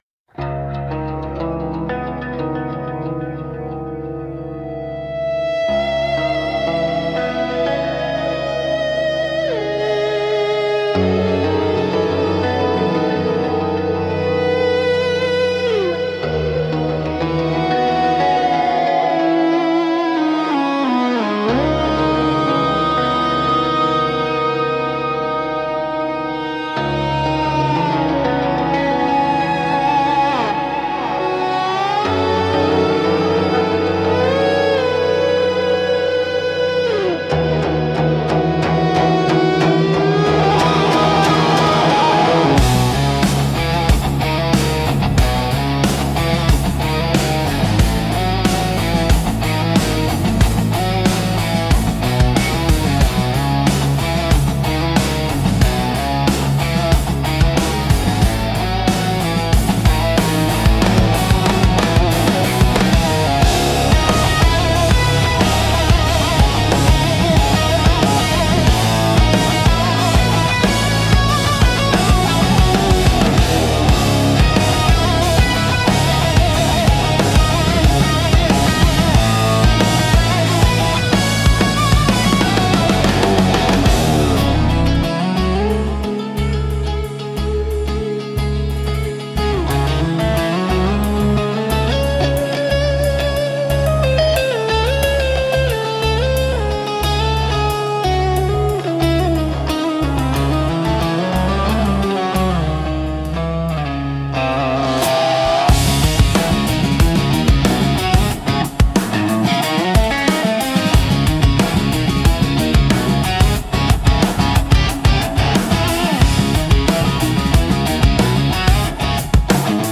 Instrumental - As the Heat Leaves the Asphalt 5.05